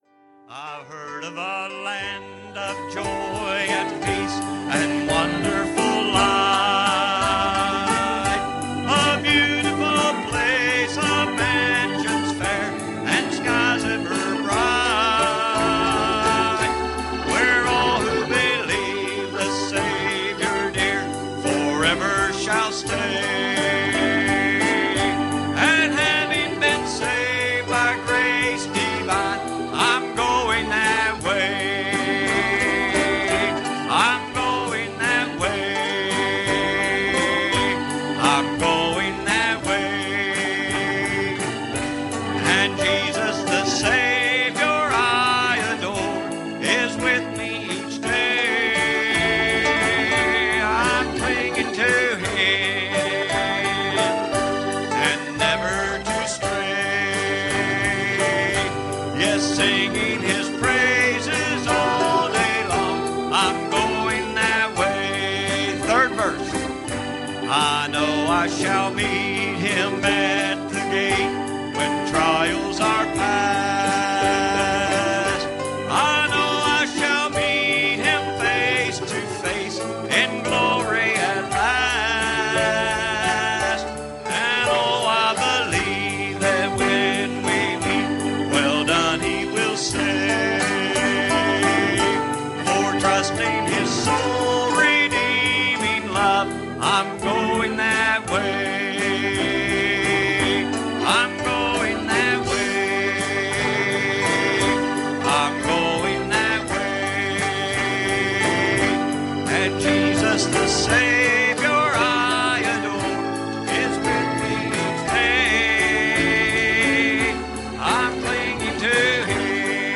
Series: Sunday Evening Services
Service Type: Sunday Evening